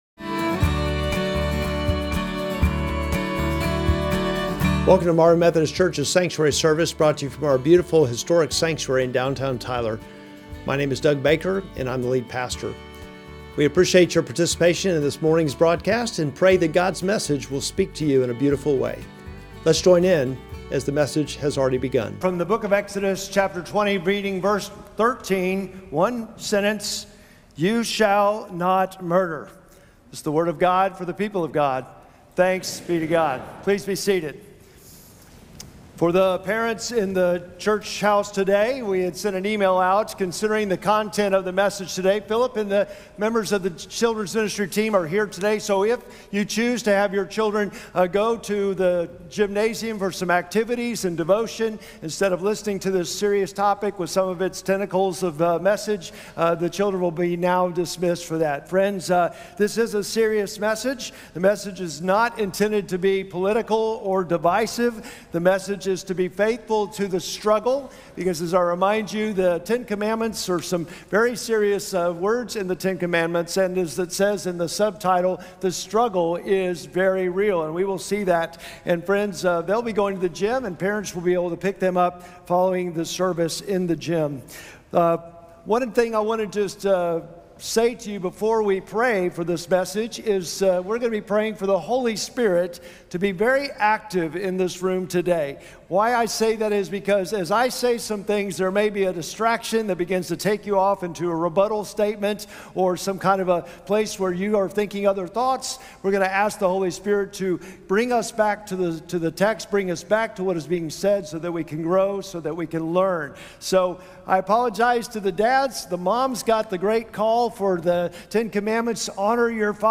Sermon text: Exodus 20:13